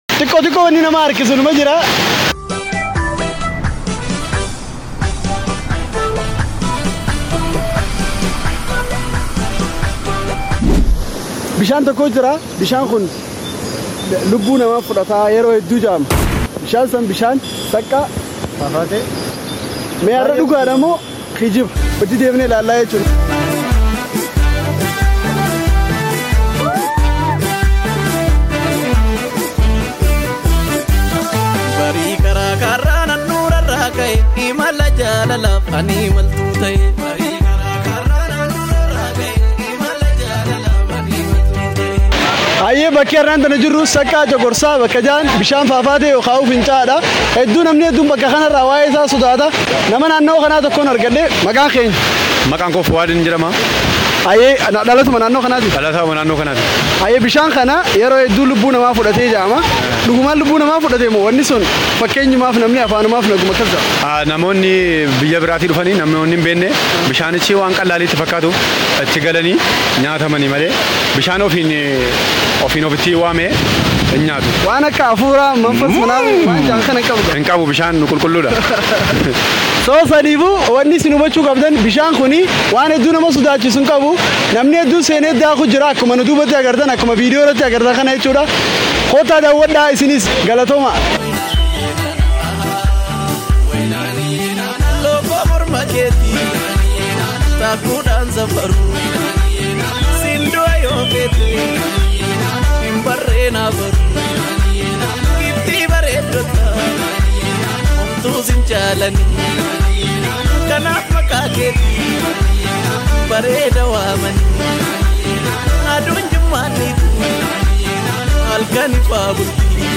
Seka Waterfall : Amazing Falls Sound Effects Free Download